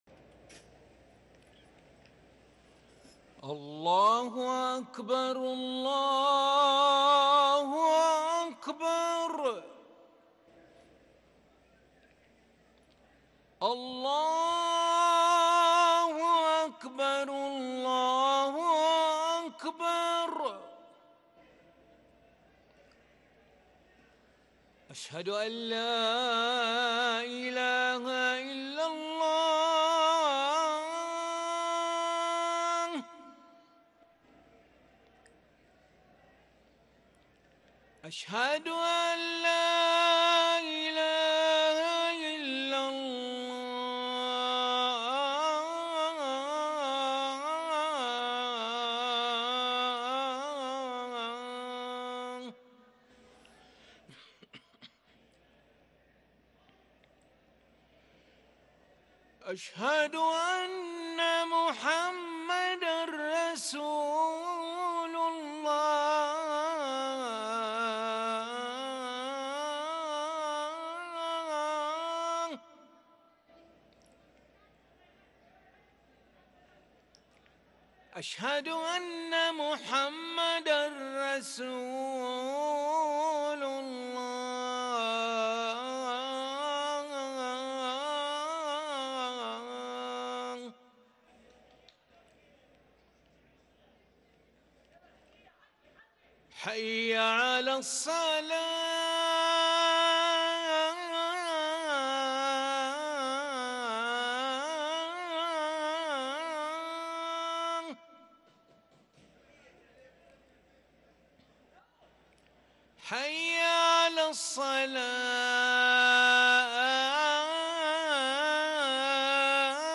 أذان العشاء للمؤذن علي ملا الخميس 10 ربيع الأول 1444هـ > ١٤٤٤ 🕋 > ركن الأذان 🕋 > المزيد - تلاوات الحرمين